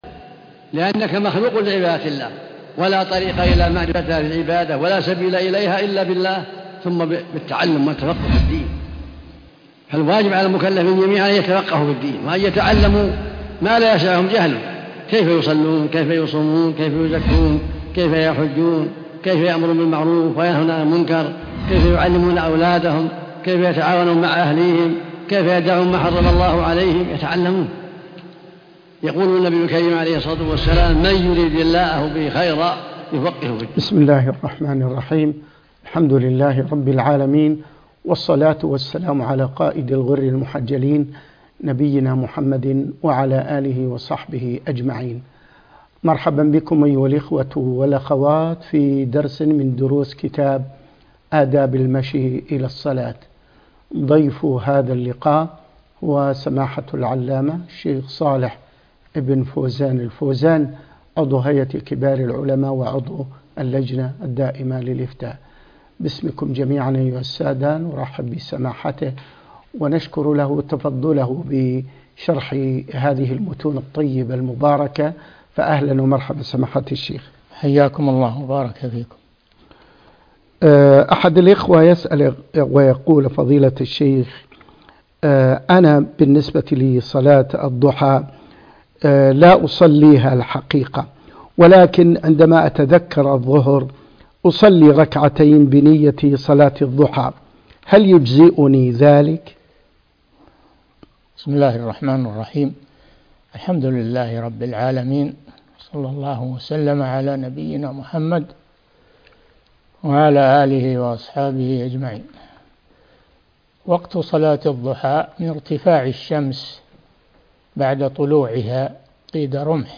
الدرس الثاني عشر- أداب المشي إلى الصلاة (3) - الشيخ صالح بن فوزان الفوازان